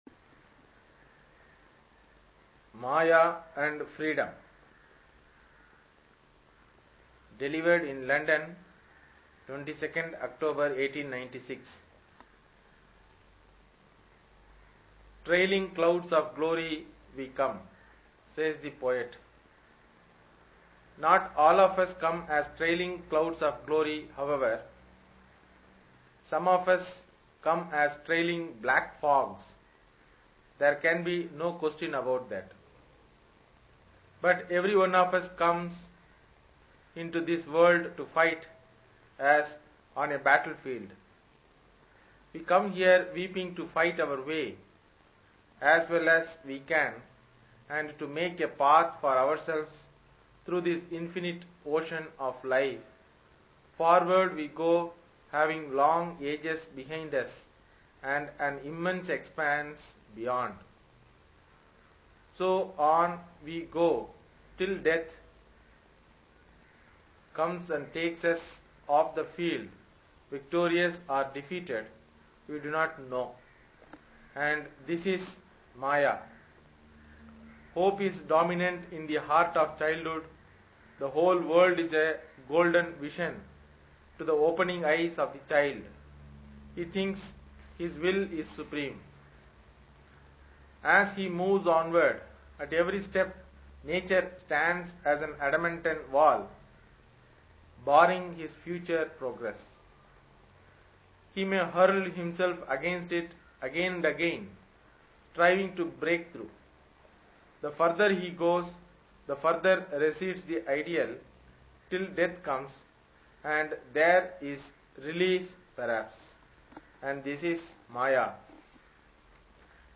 Readings from The Complete works of Swami Vivekananda